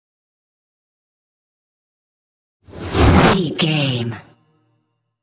Trailer dramatic raiser short flashback
Sound Effects
In-crescendo
Thriller
Atonal
intense
tension
dramatic
riser